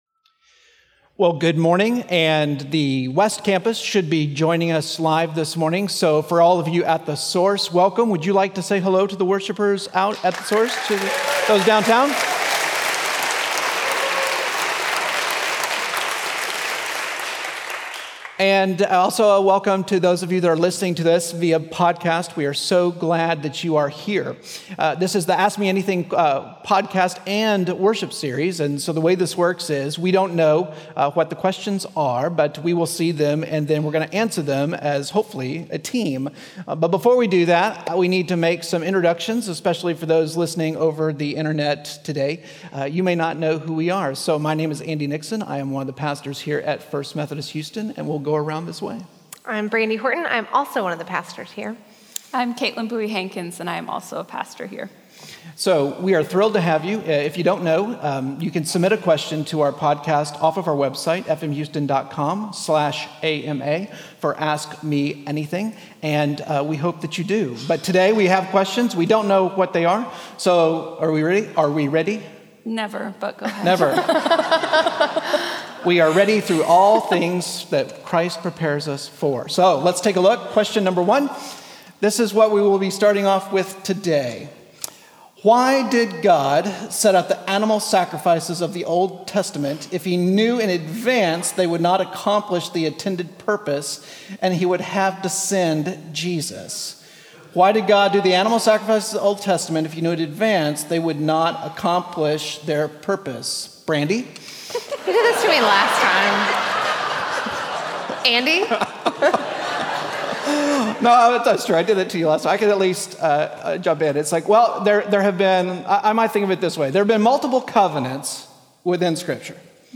Series: Ask Me Anything 2019 Service Type: Traditional https